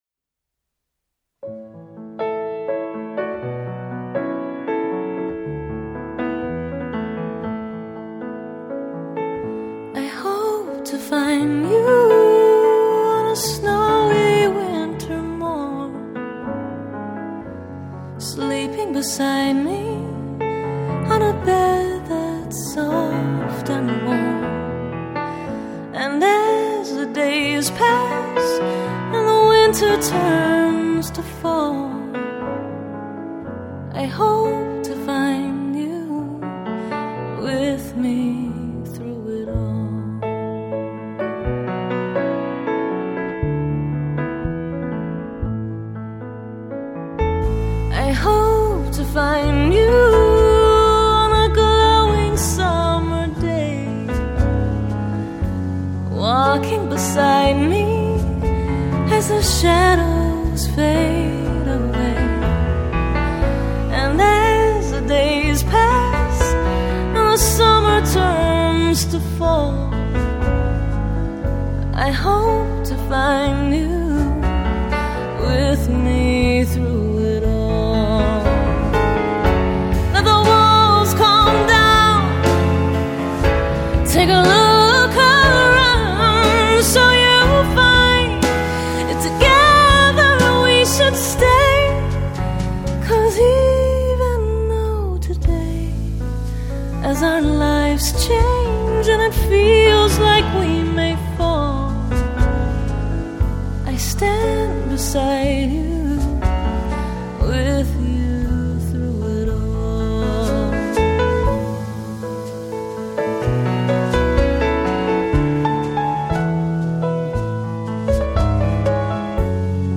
pop/rock/soul singer & songwriter based in New York City
A live recording of this song
expressive, heartfelt vocals and her graceful piano playing